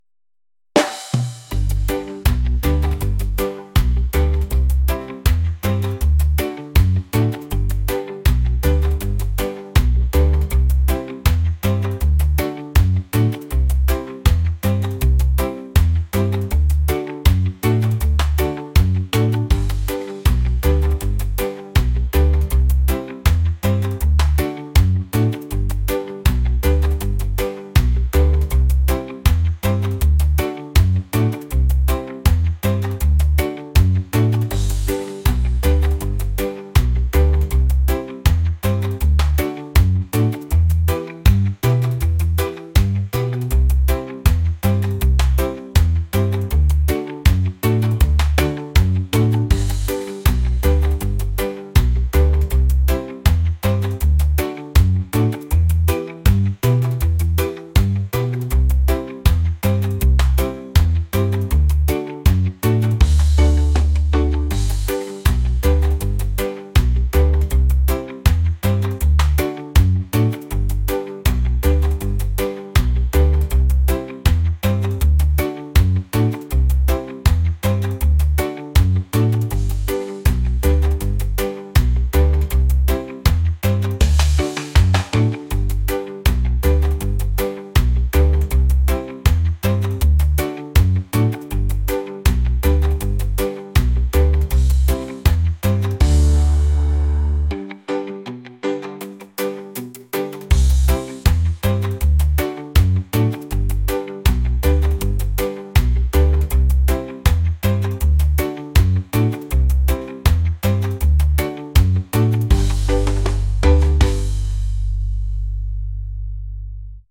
reggae | lofi & chill beats | soul & rnb